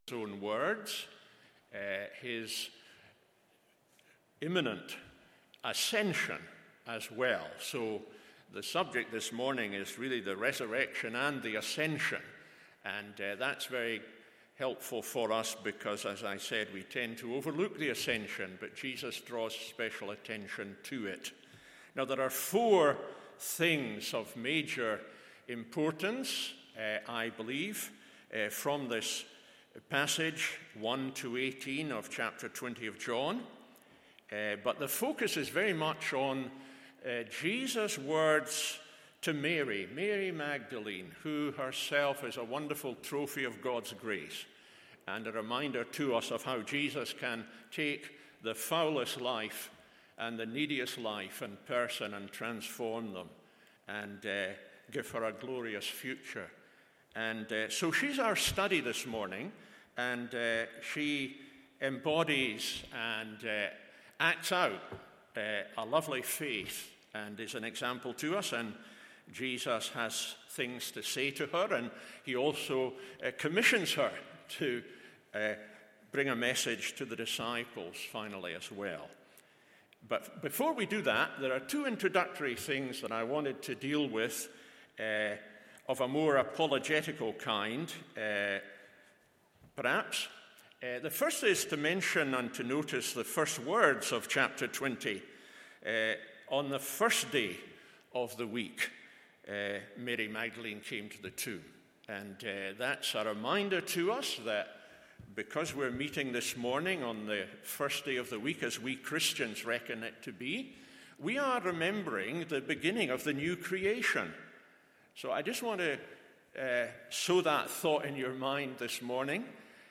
RESURRECTION SUNDAY SERVICE JOHN 20:1-18…